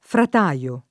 vai all'elenco alfabetico delle voci ingrandisci il carattere 100% rimpicciolisci il carattere stampa invia tramite posta elettronica codividi su Facebook frataio [ frat #L o ] agg.; pl. m. ‑tai — anche frataiolo [ frata L0 lo ]